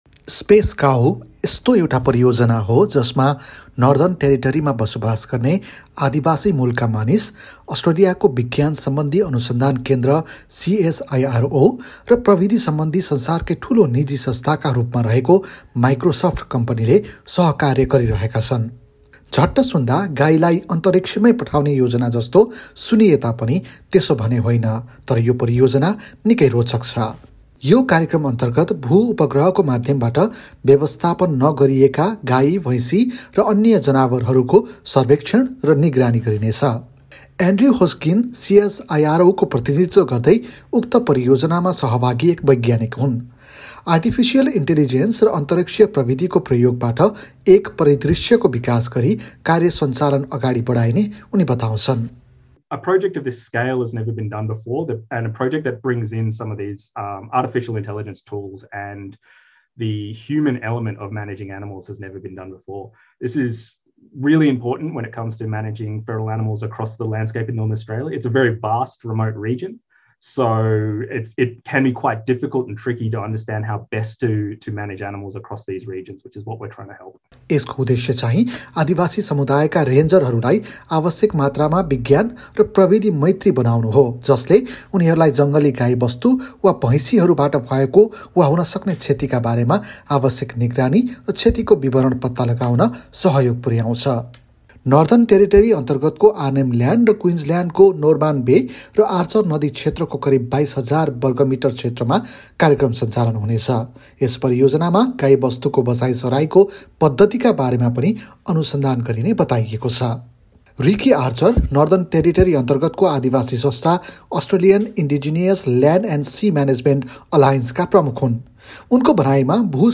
cow-report_final.mp3